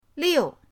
liu4.mp3